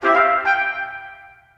collect_item_16.wav